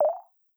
Modern UI SFX / SlidesAndTransitions